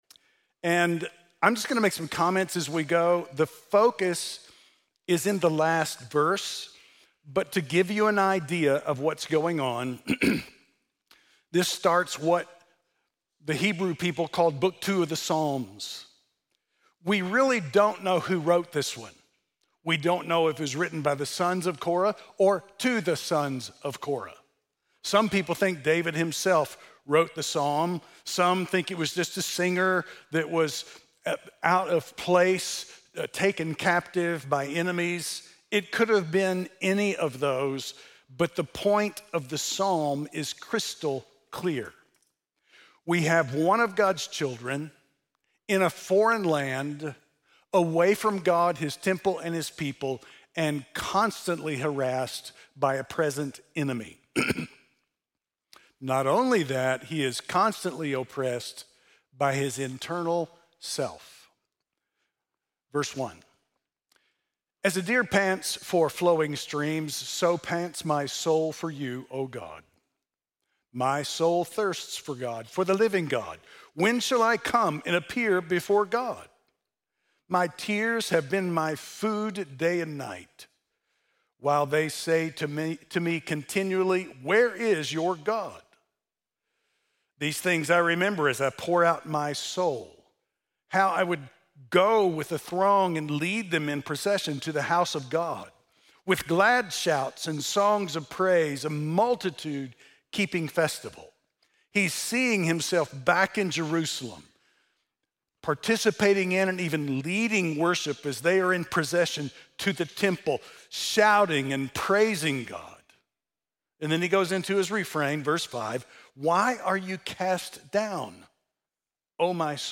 6.8-sermon.mp3